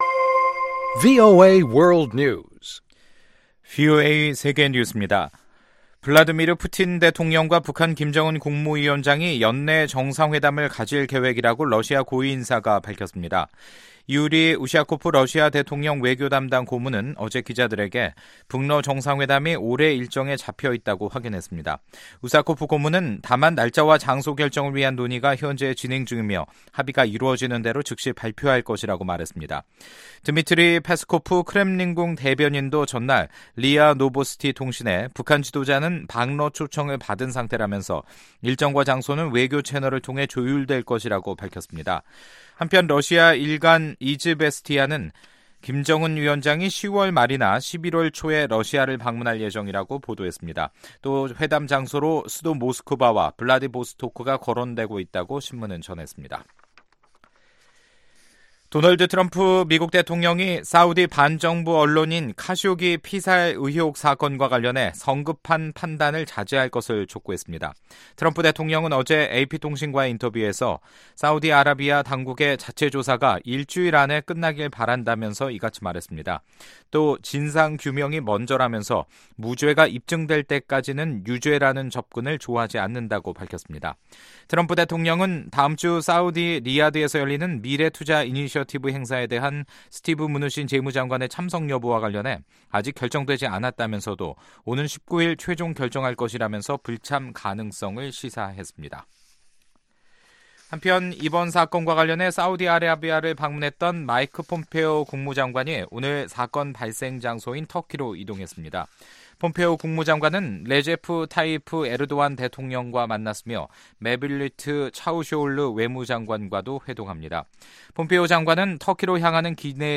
VOA 한국어 간판 뉴스 프로그램 '뉴스 투데이', 2018년 10월 17일 2부 방송입니다. 미 국무부는 문재인 한국 대통령의 유엔 대북 제재 완화 언급과 관련해 제재는 유지할 것이라는 기존 입장을 재확인 했습니다. 미국과 한국 사이의 군사 협력은 여전히 지속되고 있다고 제임스 매티스 미 국방장관이 밝혔습니다.